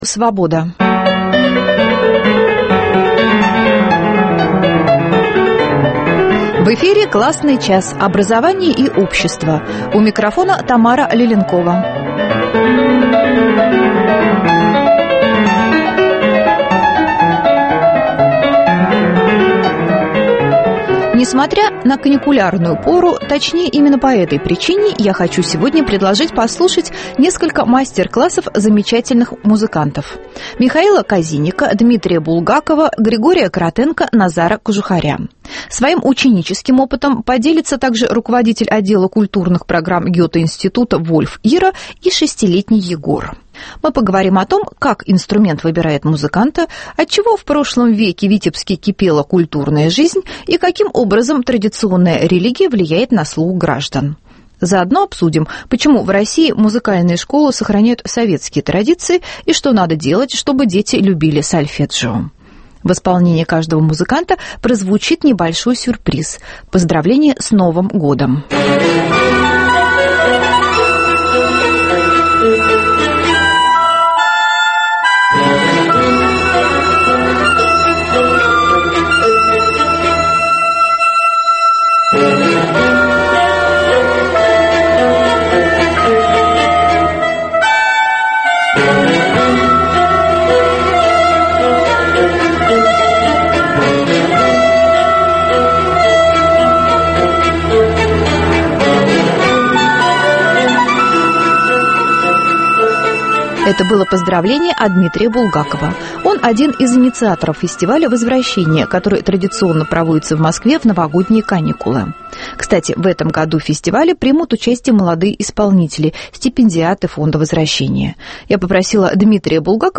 Что надо делать, чтобы дети любили сольфеджио и почему в России музыкальные школы сохраняют советские традиции? Обсуждают музыканты